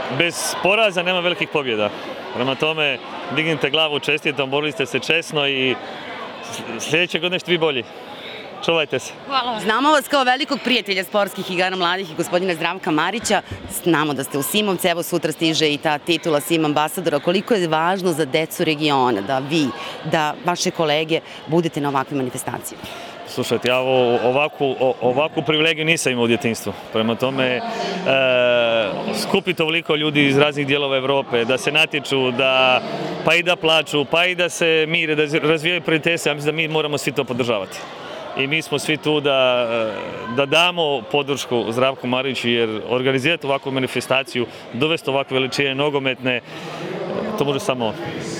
Cijelu izjavu Darija Srne poslušajte u nastavku: